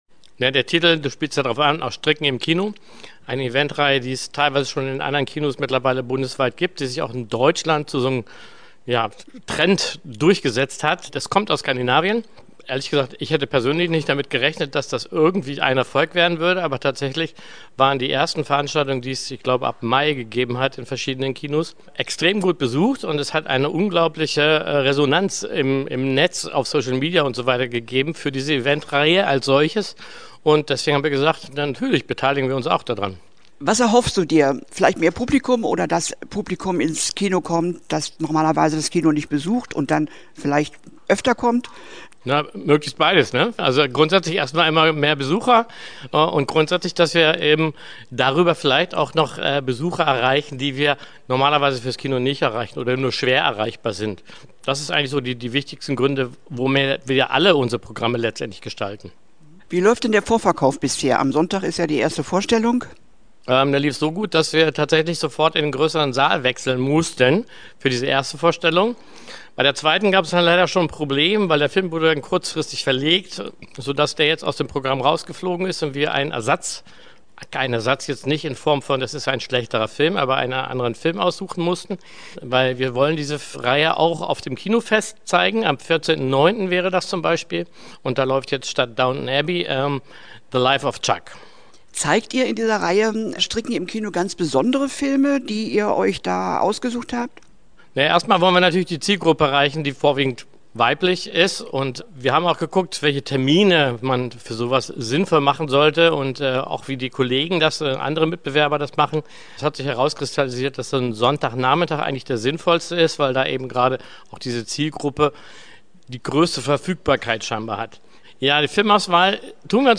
Interview-Stricken-im-Kino.mp3